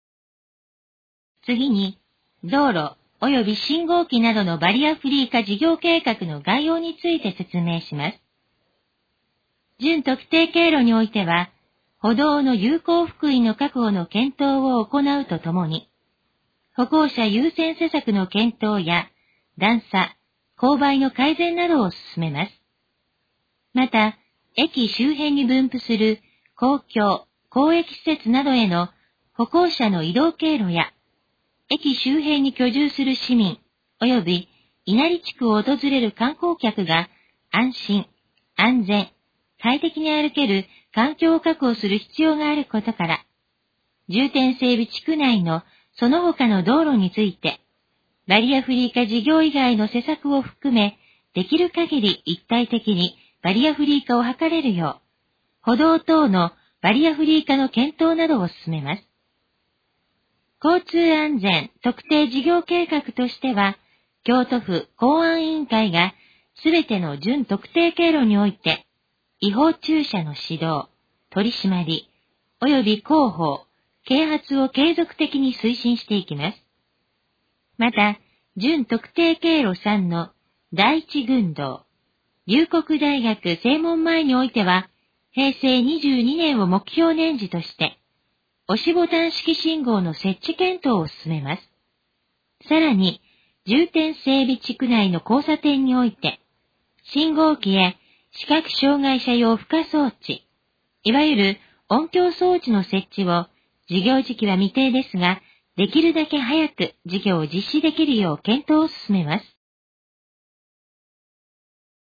以下の項目の要約を音声で読み上げます。
ナレーション再生 約218KB